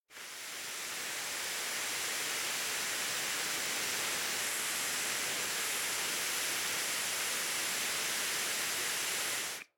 Terrarum/assets/mods/basegame/audio/effects/explosion/fuse_continue.ogg at a236f496410e928fa4b32c28ea16c261f8635e9a
fuse_continue.ogg